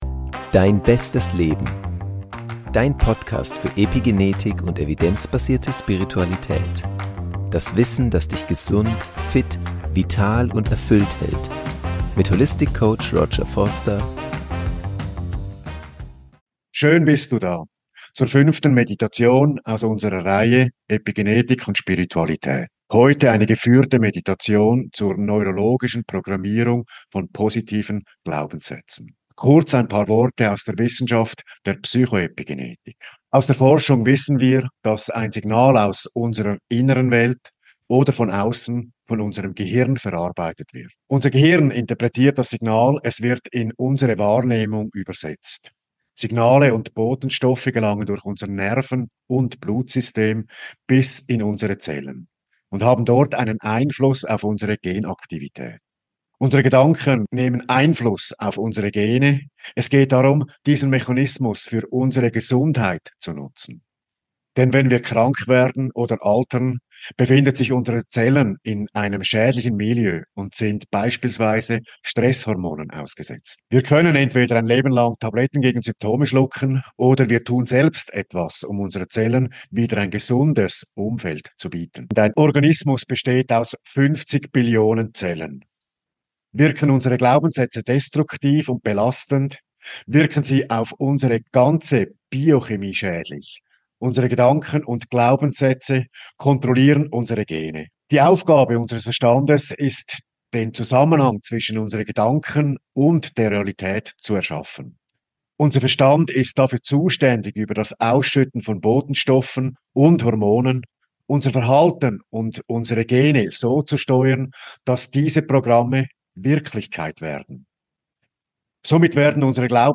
In diesem Video erhältst Du eine geführte Meditation zur neurologischen Programmierung von positiven Glaubenssätzen mit binauralen Beats (6 Hz, Theta Frequenz). Die Meditation basiert auf den Erkenntnissen der Psycho Epigenetik.